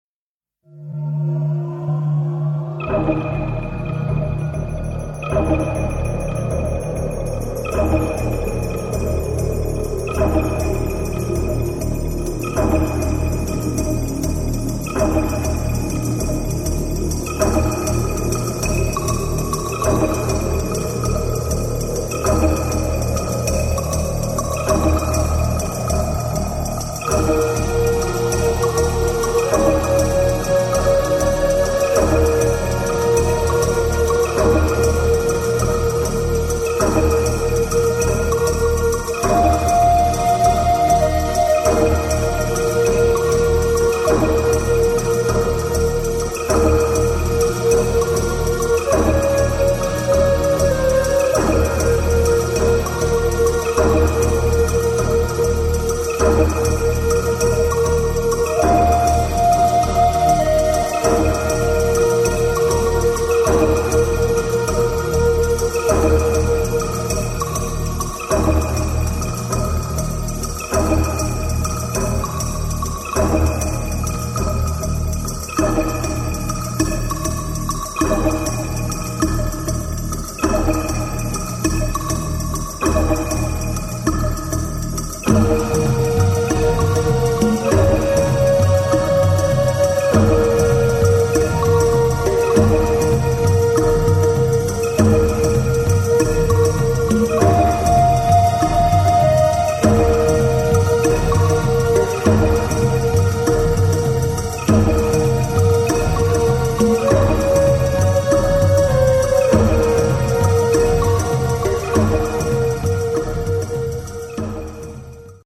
Often ethereal